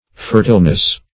\fer"tile*ness\